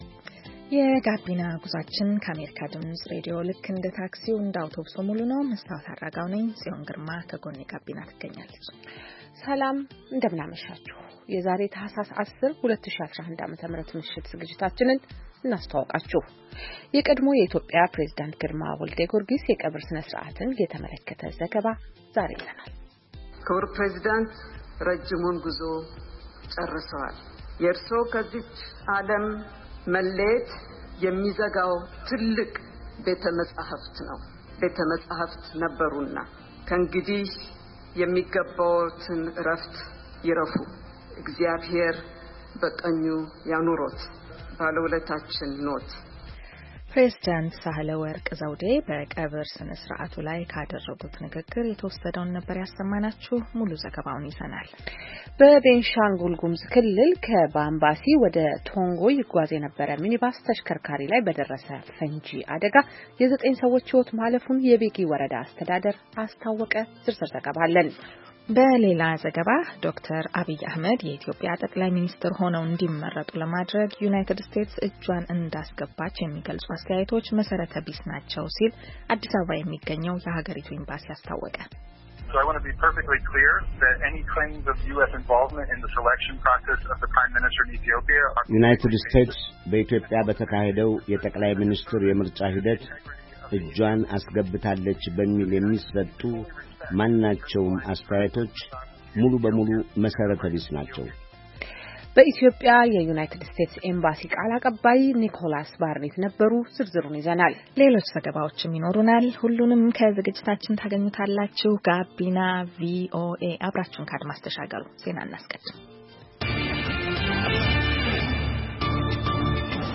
Gabina VOA is designed to be an infotainment youth radio show broadcasting to Ethiopia and Eritrea in the Amharic language. The show brings varied perspectives on issues concerning young people in the Horn of Africa region.